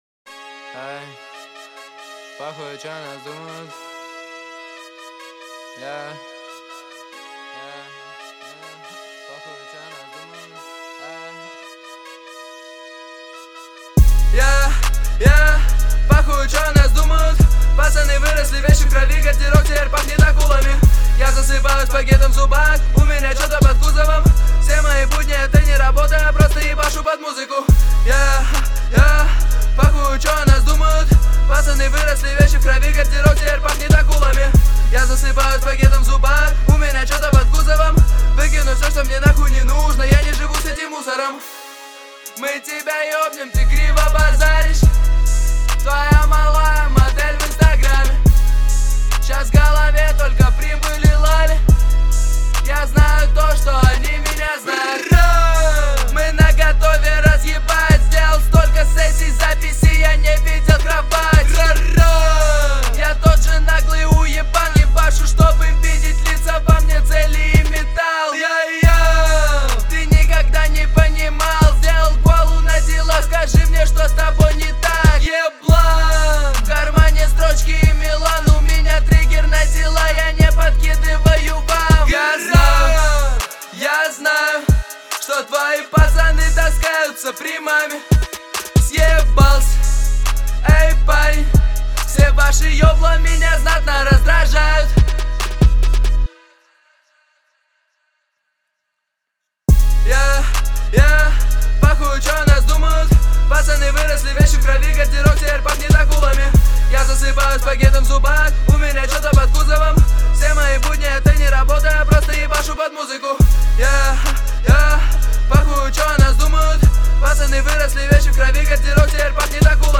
Я убрал 1 лид и 1 бэкс Теперь структура следующая Double left 40% Lead Double right 40% Back airs Звук стал чище, но при этом пропал шарм по сравнению с тем когда лида было 2, как то сыро чтоли, зажато, даже незнаю как обьяснить, энергии не хватает.